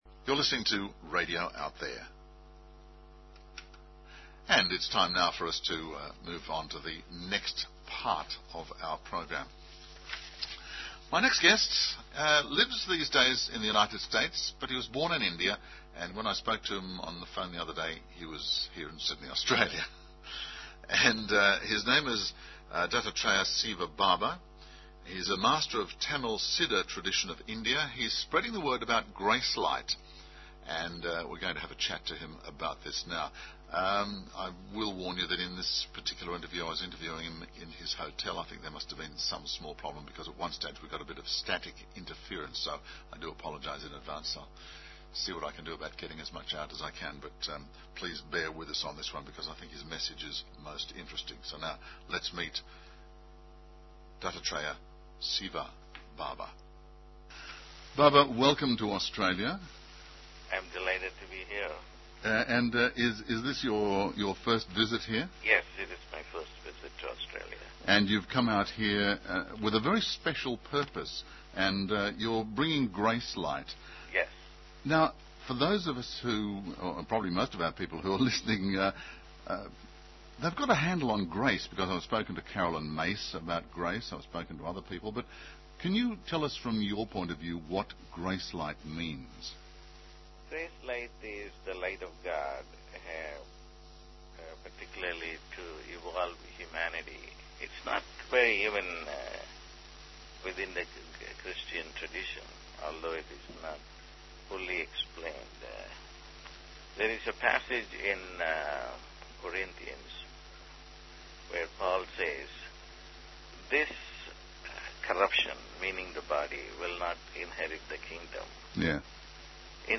The interview is approximately 12 minutes in duration.